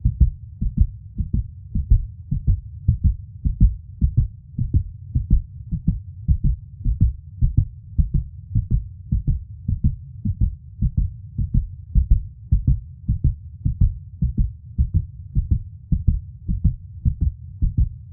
heartbeat_strong.mp3